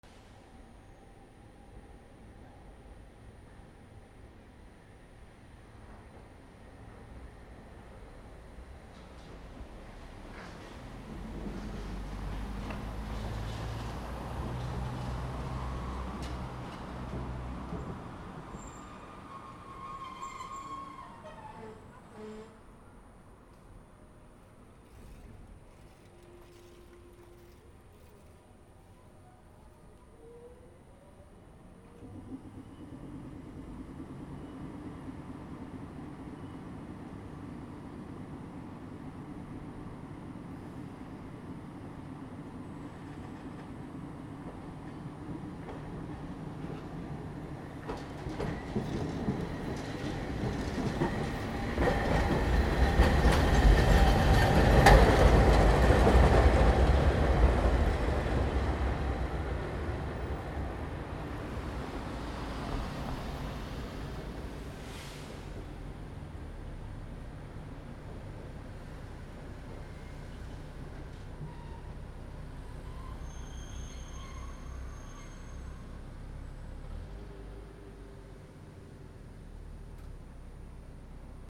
路面電車 6
/ E｜乗り物 / E-65 ｜路面電車 / 再構成用